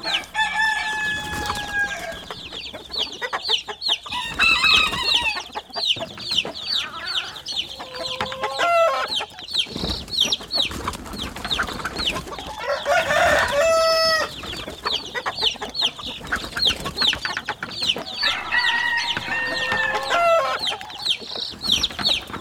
chickens.wav